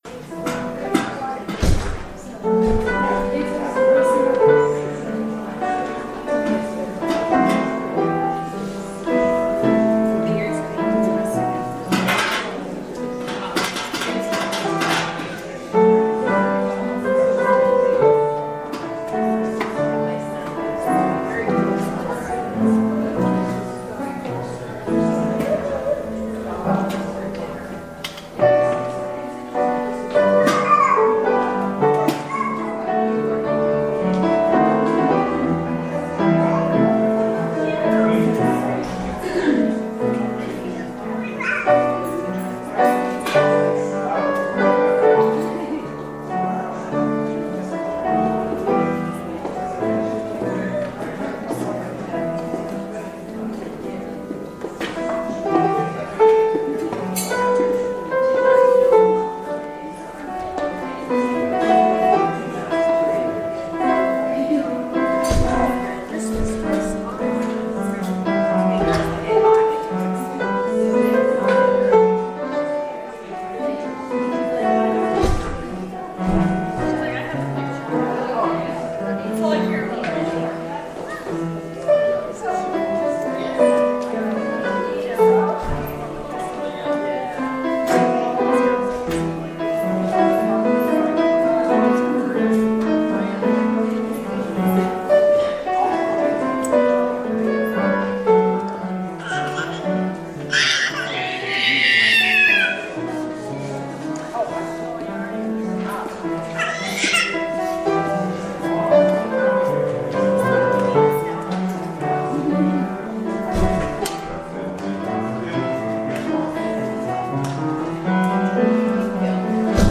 Audio recording of the 10am hybrid/streamed service (in the Parish Hall)
We have been worshiping in the Parish Hall, which doesn’t have the same recording capabilities. Part of the service streamed remotely from the Narthex was lost due to connectivity issues.